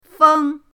feng1.mp3